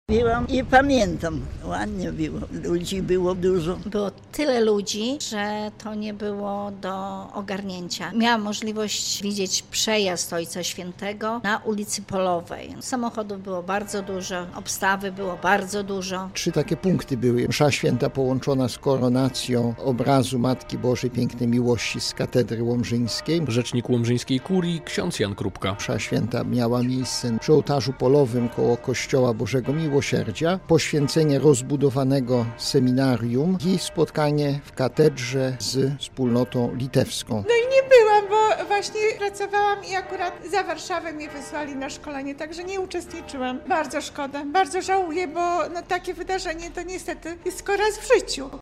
Jak te wydarzenia pamiętają mieszkańcy?